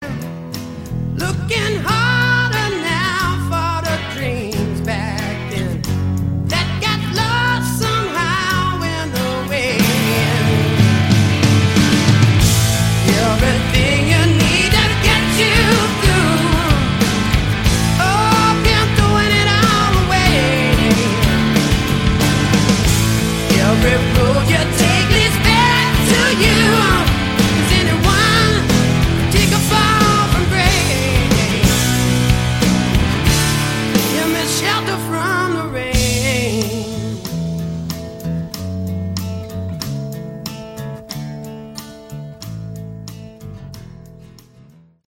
Category: Hard Rock
Lead Vocals
Drums
Lead Guitar
Bass
Backing Vocals
Great bluesy hard rock record
This is very good blues hard rock.